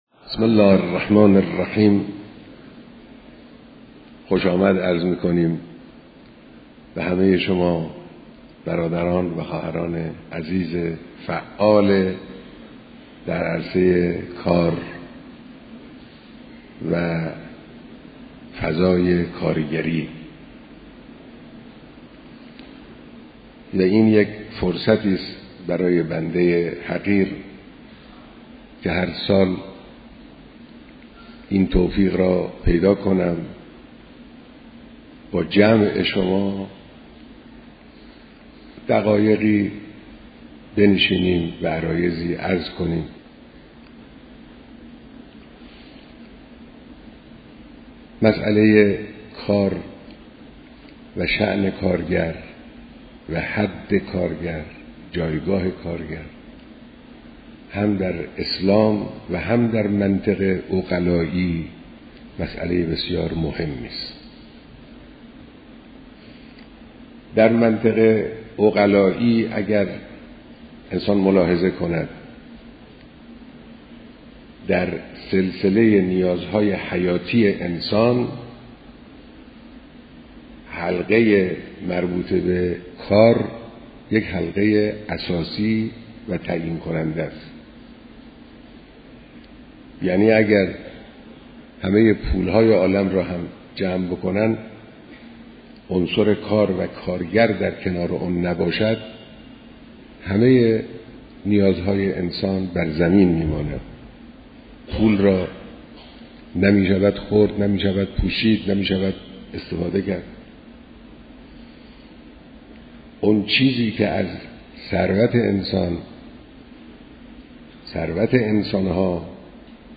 بیانات در دیدار هزاران نفر از كارگران سراسركشور